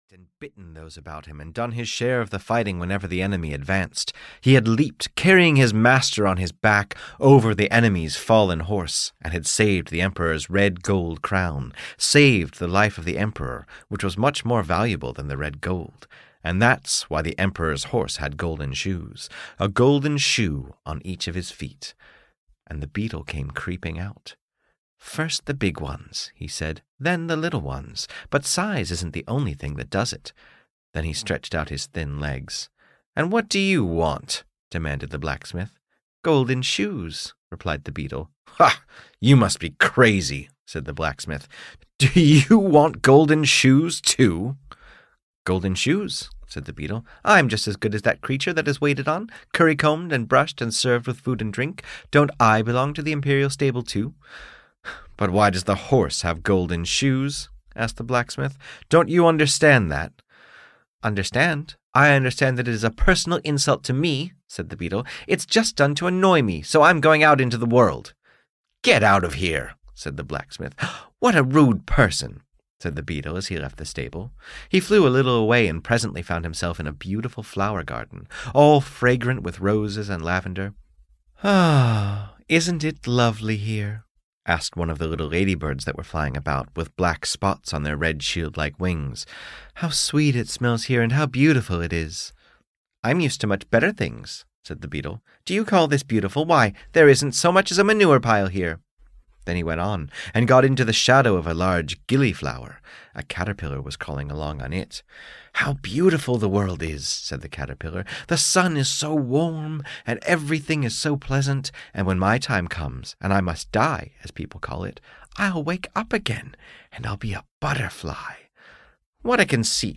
The Beetle (EN) audiokniha
Ukázka z knihy